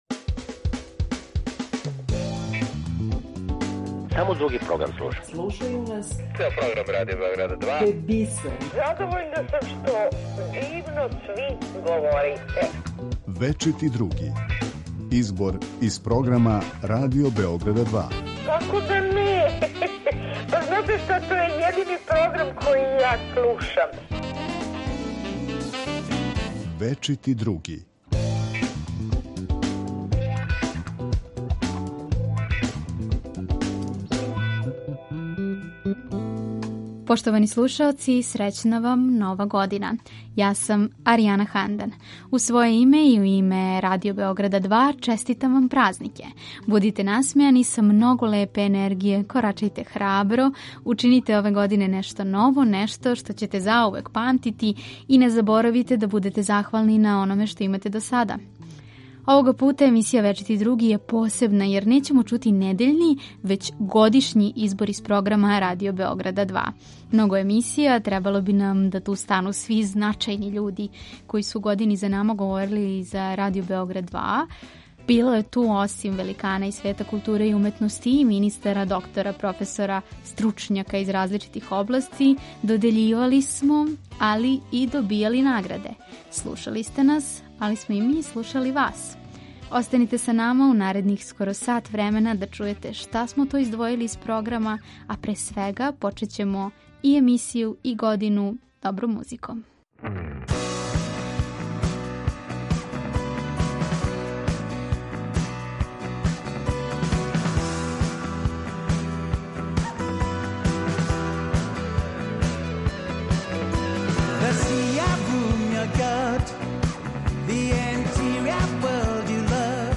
У новогодишњој емисији Вечити Други Радио Београда 2 чућете наш избор делова из емисија које су се емитовале током 2021. године.
Подсетићемо се шта су за наш програм у 2021. години говорили Његова светост патријарх српски господин Порфирије, прослављени редитељ Дејан Мијач, песникиња и драмска списатељица проф. Милена Марковић, глумац Драган Бјелогрлић и др. Чућемо и део нашег документарног програма, али и шта сте ви говорили, поштовани слушаоци.